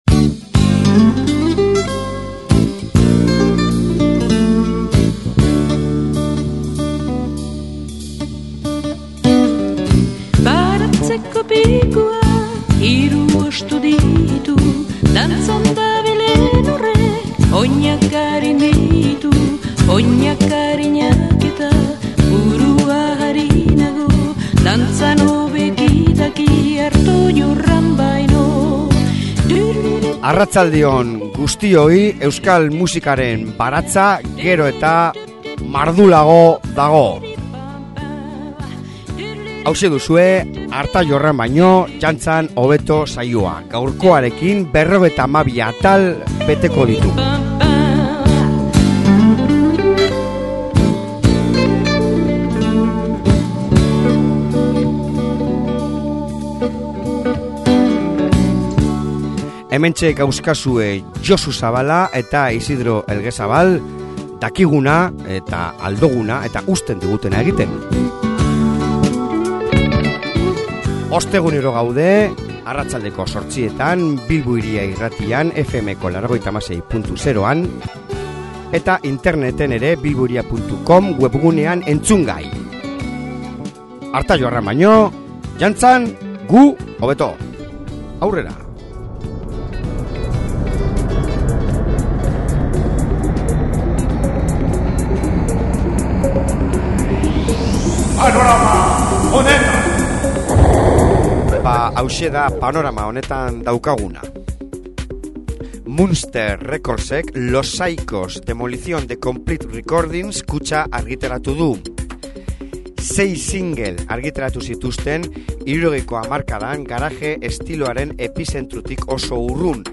Elkarrizketa brutala!